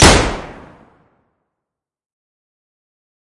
枪支 " 战斗步枪
描述：我创建的各种枪支效果：不同的军鼓和地板tomsLight开关用于触发点击投掷硬币到用碗壳体接触式麦克风记录的碗中
声道立体声